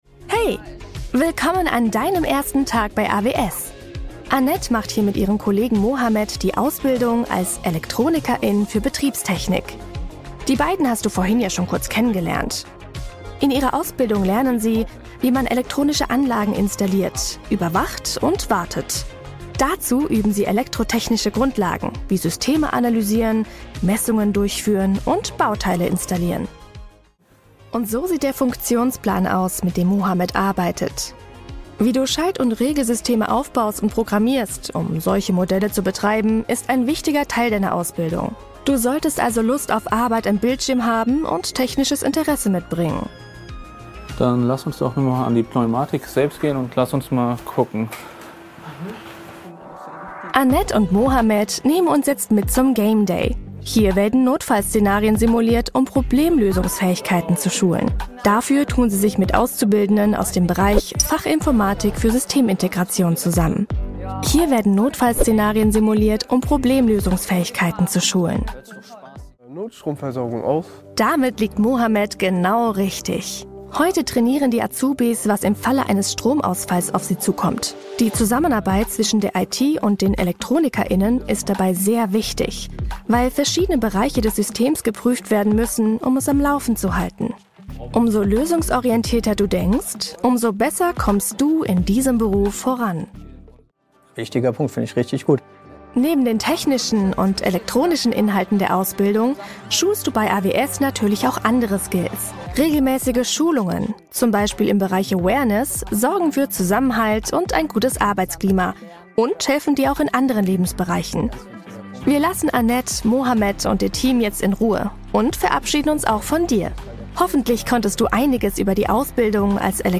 Ausbildungsvideo – Betriebstechnik
warm mezzo weiblich jung empathisch dynamisch
11-Erklaerfilm-DET-Betriebstechnik.mp3